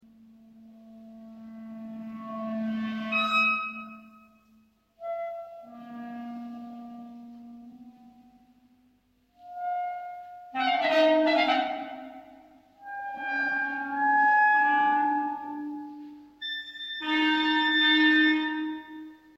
clarinets